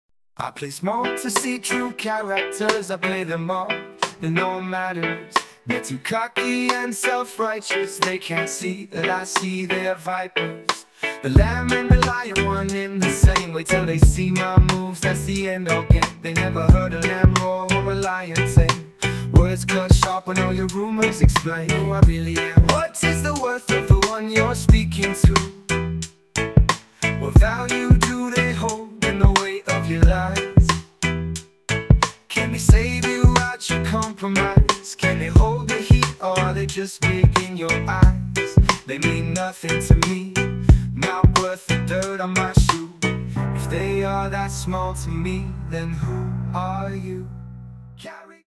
Reggae (island)